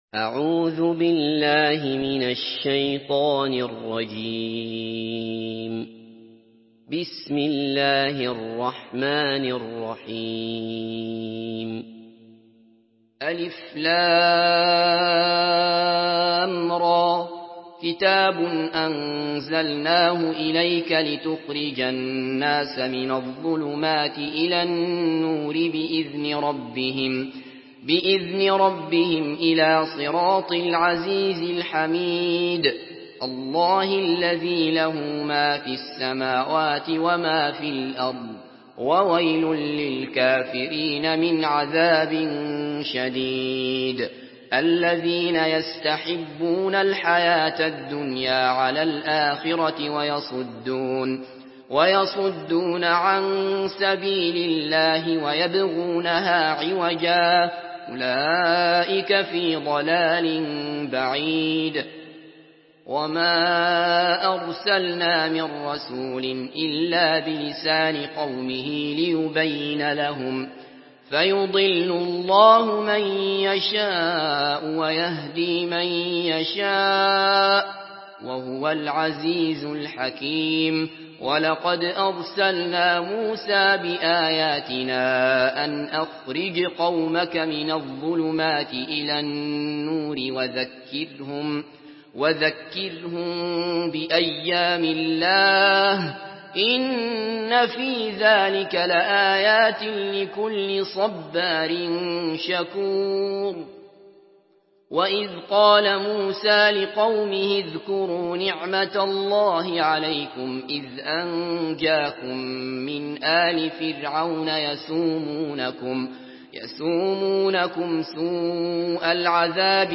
Surah إبراهيم MP3 by عبد الله بصفر in حفص عن عاصم narration.